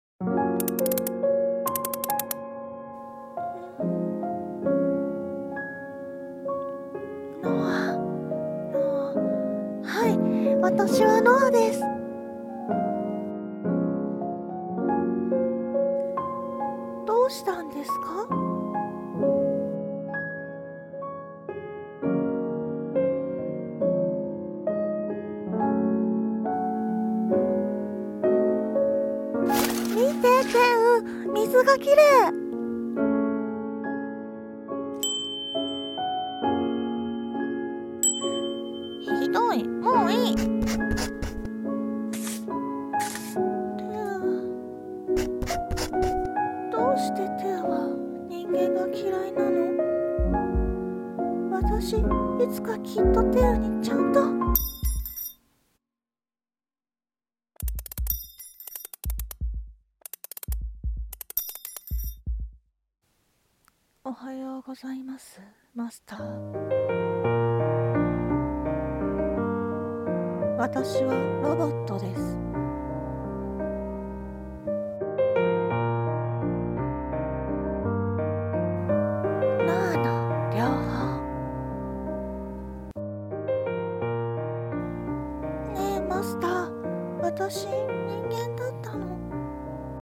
さんの投稿した曲一覧 を表示 【映画予告風声劇】ノアの療法《コラボ用》